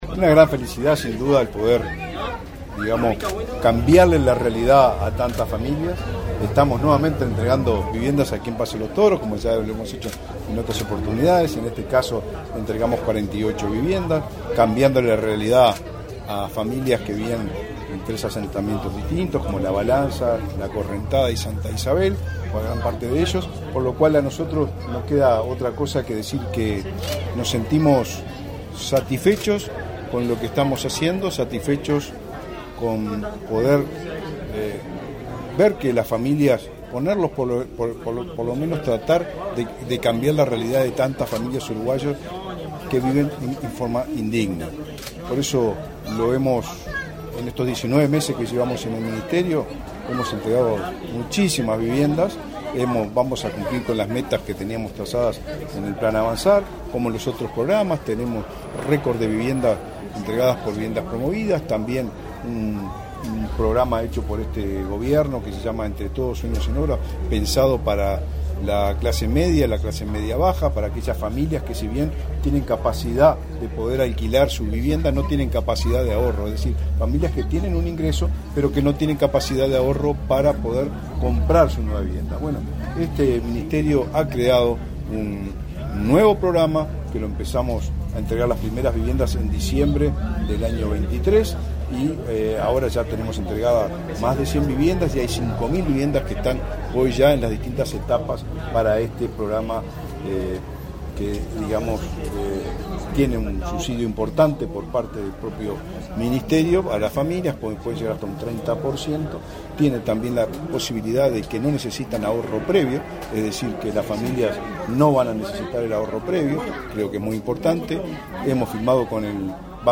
Declaraciones a la prensa del ministro del MVOT, Raúl Lozano
Declaraciones a la prensa del ministro del MVOT, Raúl Lozano 05/02/2025 Compartir Facebook X Copiar enlace WhatsApp LinkedIn Tras participar en el acto de entrega de 48 viviendas del Plan Avanzar en Paso de los Toros, este 5 de febrero, el ministro de Vivienda y Ordenamiento Territorial (MVOT), Raúl Lozano, realizó declaraciones a la prensa.